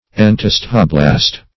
Search Result for " entosthoblast" : The Collaborative International Dictionary of English v.0.48: Entosthoblast \En*tos"tho*blast\, n. [Gr.